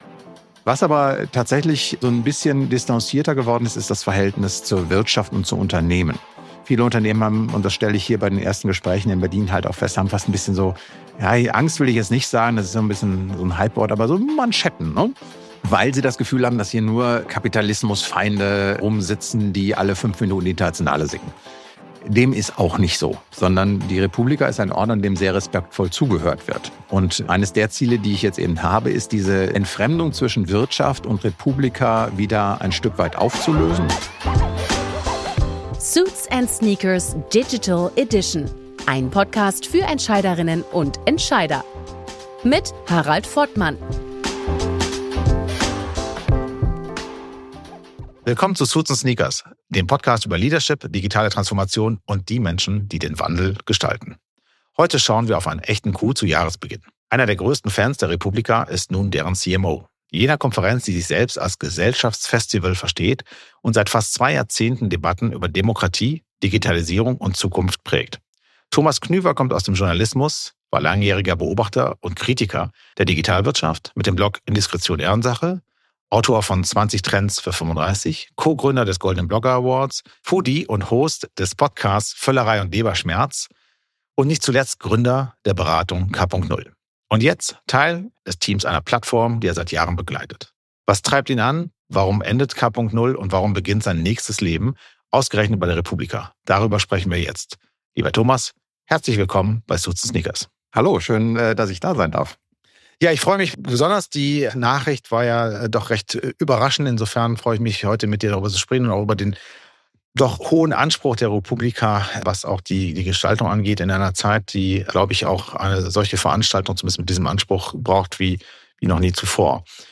In diesem Gespräch wird die re:publica als Plattform für den Dialog zwischen Wirtschaft und Gesellschaft beleuchtet.